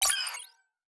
Game Warning Sound 4.wav